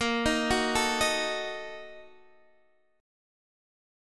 BbM7sus4#5 chord